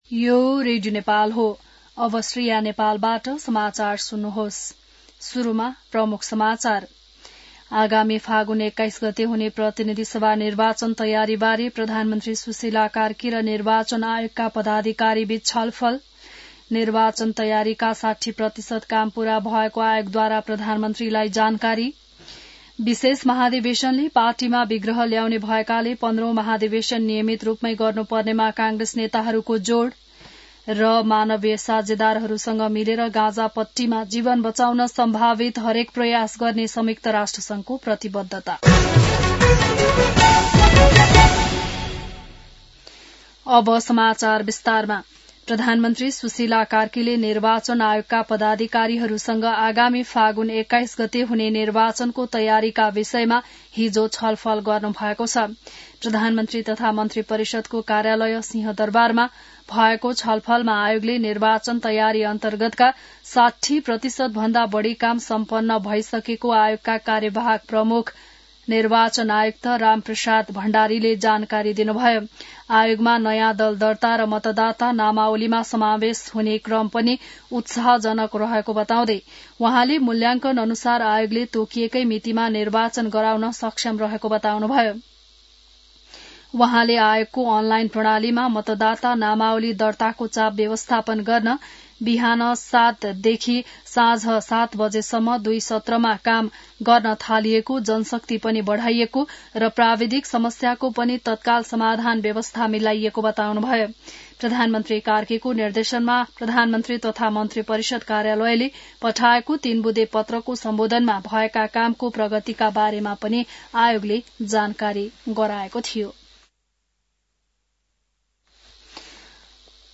बिहान ९ बजेको नेपाली समाचार : २७ कार्तिक , २०८२